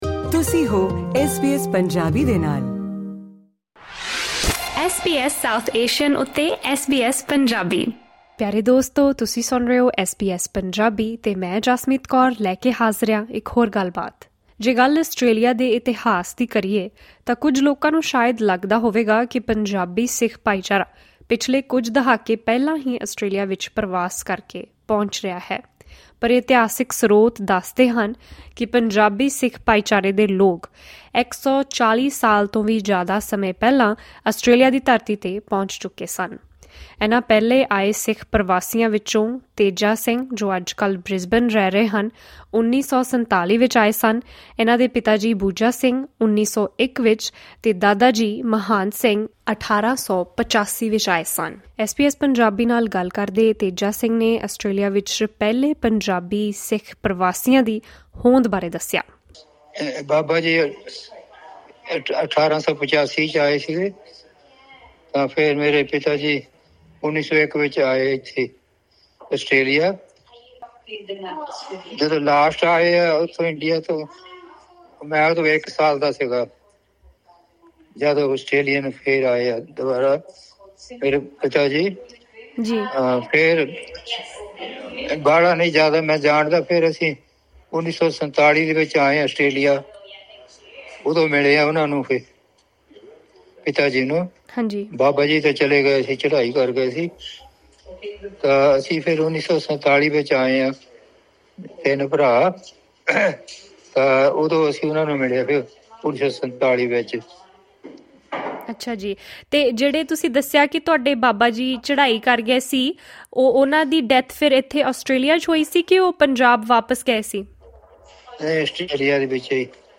ਖਾਸ ਗੱਲਬਾਤ